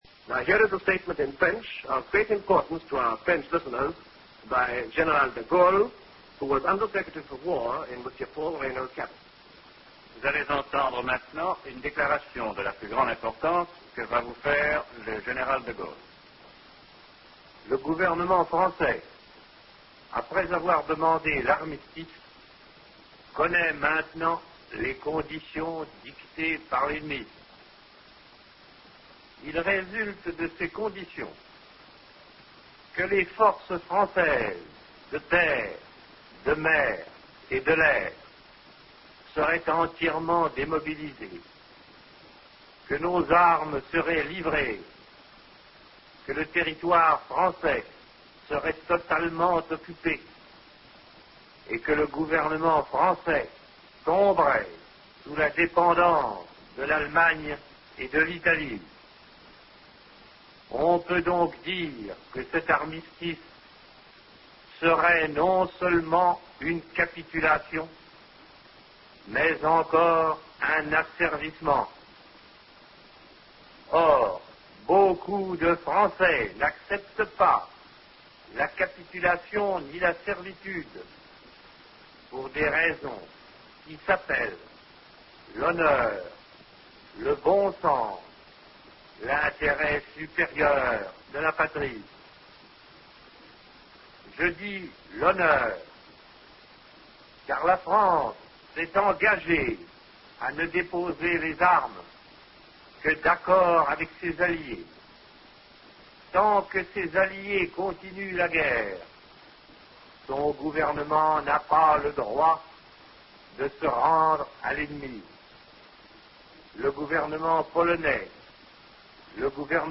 L'appel du 18 Juin 1940 n'a pas été enregistré, en cliquant sur l'affiche, vous pourrez entendre le second discours du Général de Gaulle enregistré à la BBC le 22 juin 1940